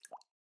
drip_water9.ogg